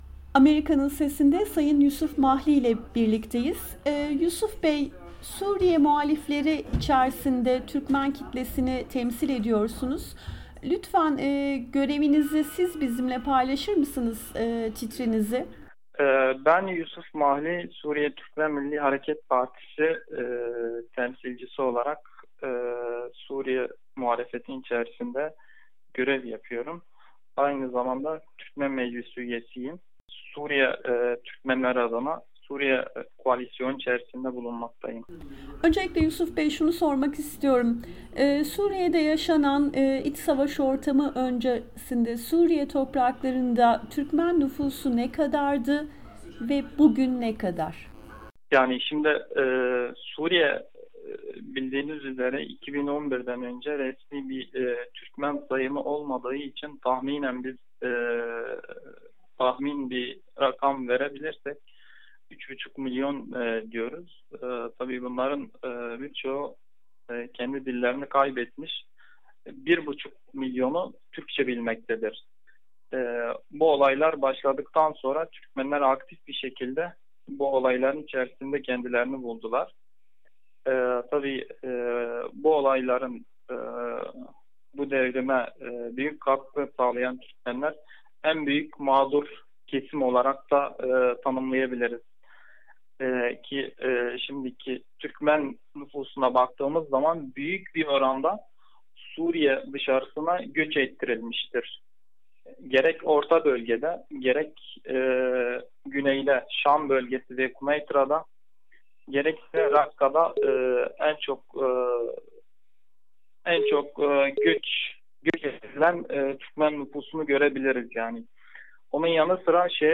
Suriye Türkmen Meclisi Başkanı Abdurrahman Mustafa ile söyleşi